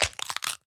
egg_crack2.ogg